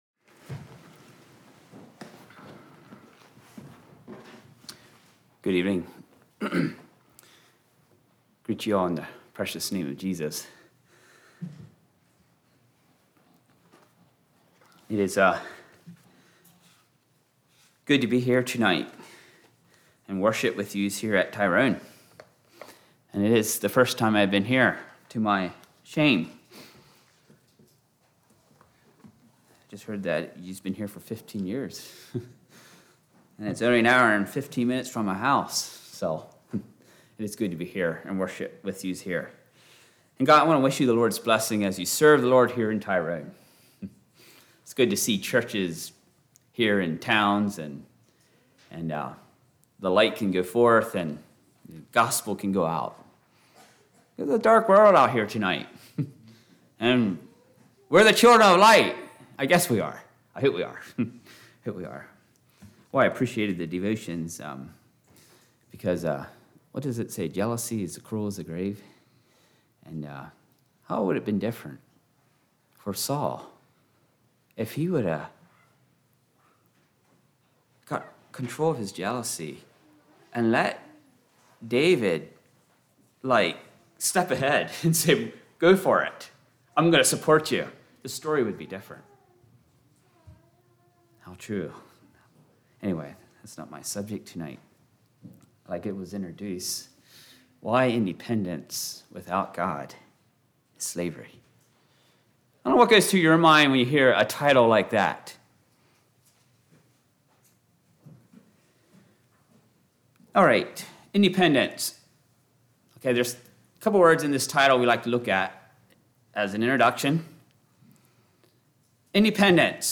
Play Now Download to Device Why Independence Without God is Slavery Congregation: Tyrone Speaker